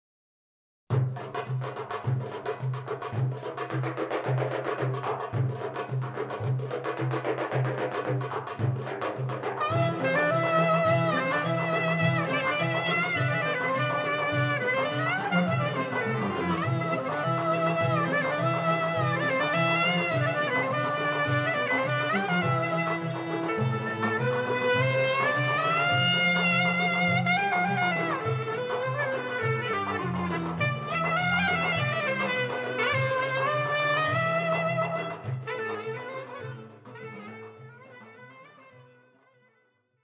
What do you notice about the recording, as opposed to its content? Recorded on November 3rd, 1999 in Istanbul at Audeon Studios